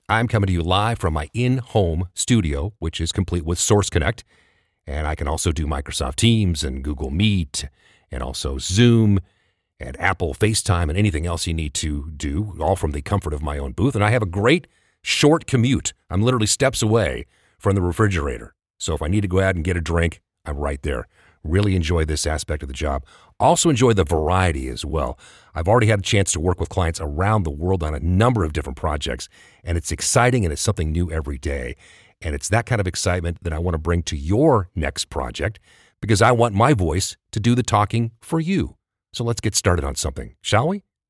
Male
Adult (30-50), Older Sound (50+)
I deliver a warm, friendly, relatable tone with clarity and articulation. I also possess a versatile and dynamic range that is energetic and enthusiastic, as well as authoritative and confident.
Studio Quality Sample
From Inside My Home Studio